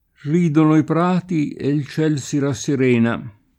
cielo [©$lo] s. m. — es.: Allora de’ cieli Ne’ lucidi porti La terra si celi [all1ra de ©$li ne lidi p0rti la t$rra Si ©$li] (Zanella) — per lo più con C- maiusc. nel sign. di «Dio»: preghi il Cielo che questo non m’accada mai [pr$gi il ©$lo ke kkU%Sto nom m akk#da m#i] (D’Annunzio); volesse il C.; grazie al C. — tronc. in qualche locuz. come a ciel sereno [a ©©$l Ser%no] e anche aiutati che il Ciel t’aiuta [aL2tati ke il ©$l t aL2ta] (ma più com.